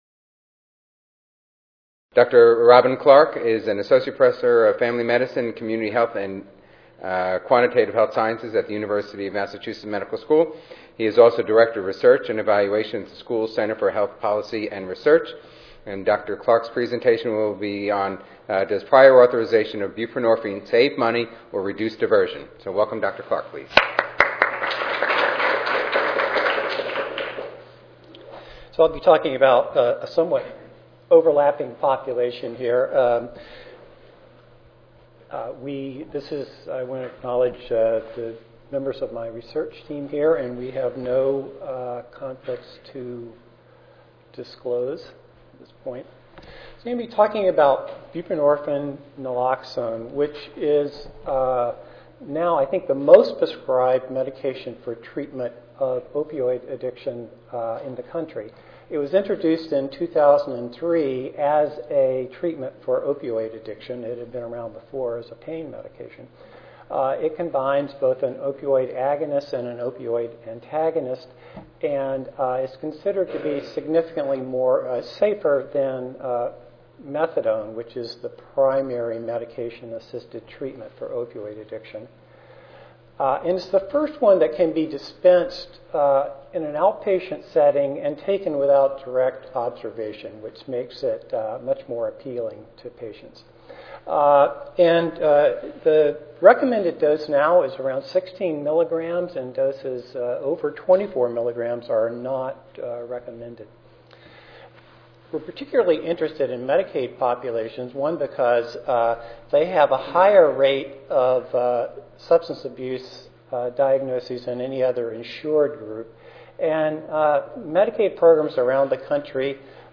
4239.0 Drug review and approval in US and Europe, adverse effects, hospital use, prior approval Tuesday, November 5, 2013: 12:30 p.m. - 2:00 p.m. Oral This session will review some emerging issues at intersection of public health and pharmacy.